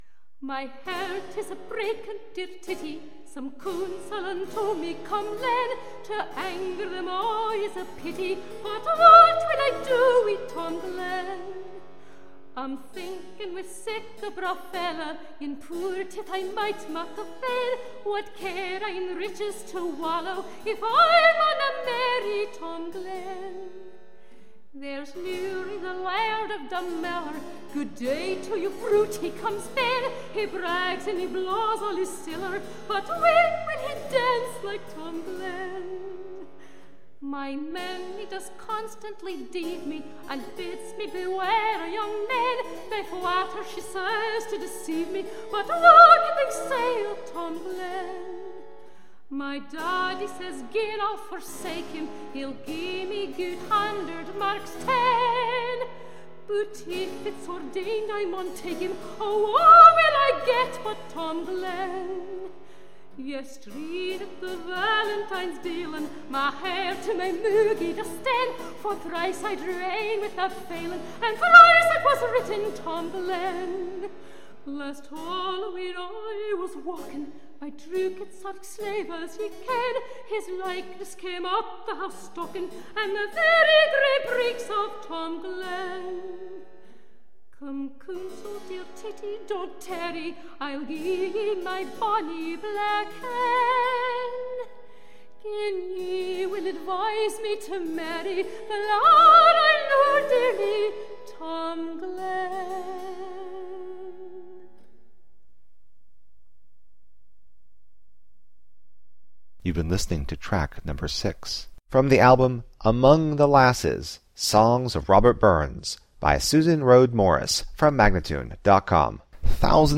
Tagged as: Classical, Folk, Celtic